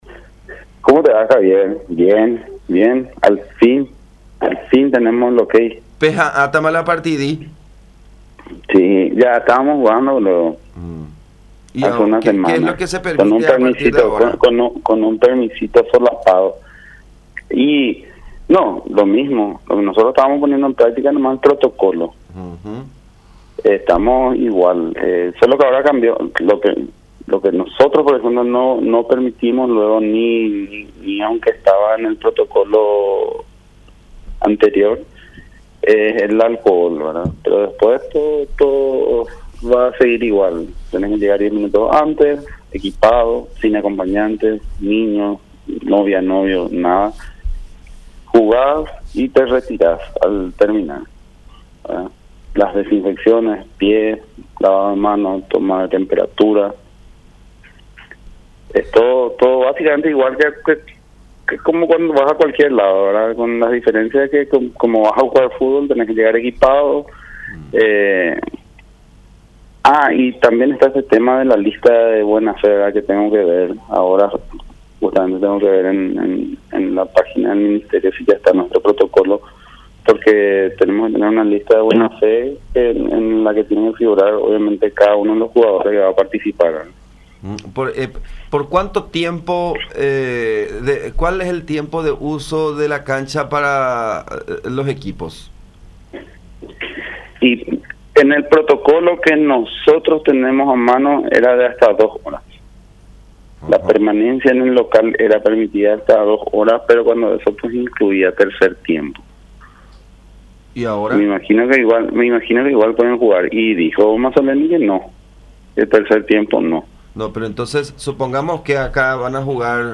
en conversación con La Unión